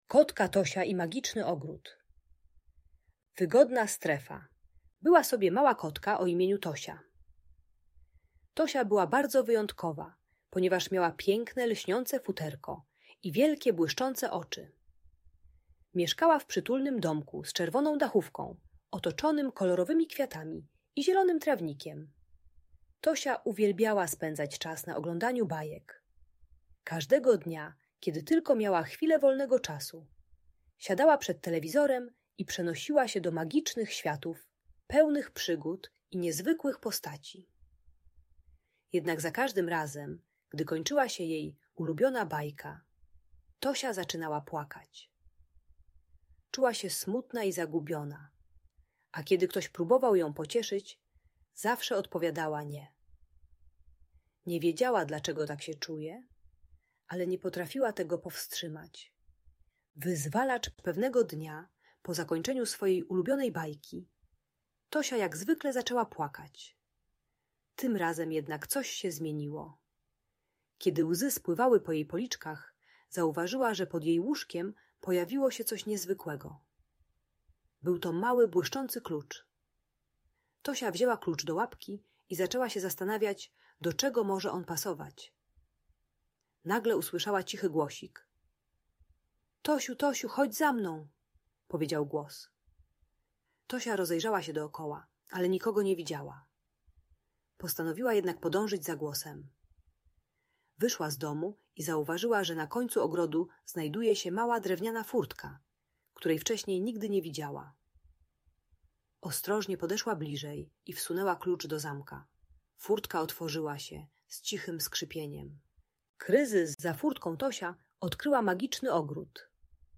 Kotka Tosia i Magiczny Ogród - Urocza opowieść dla dzieci - Audiobajka